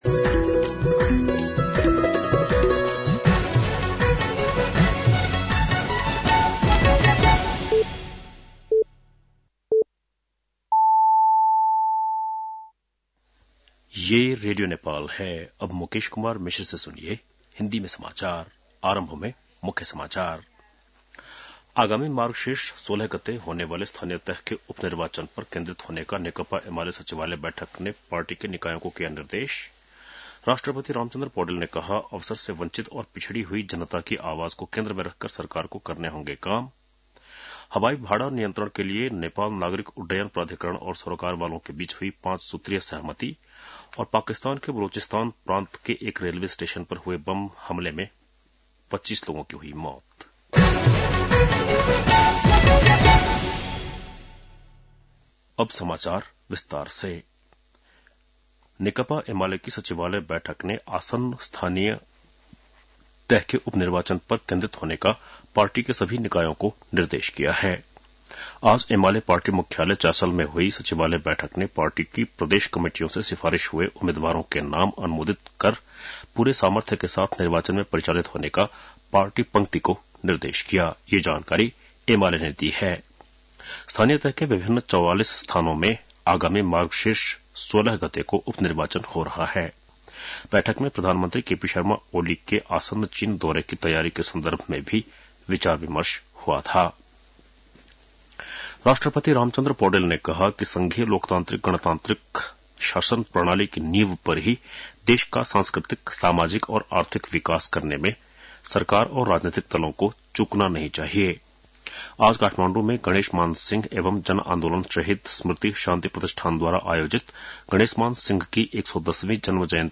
बेलुकी १० बजेको हिन्दी समाचार : २५ कार्तिक , २०८१